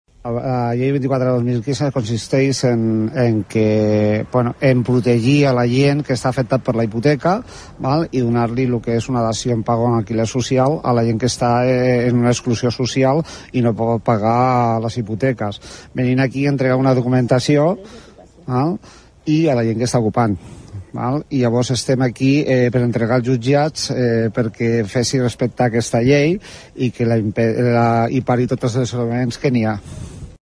Ho explicava un membre de la PAH en declaracions a Ràdio Arenys.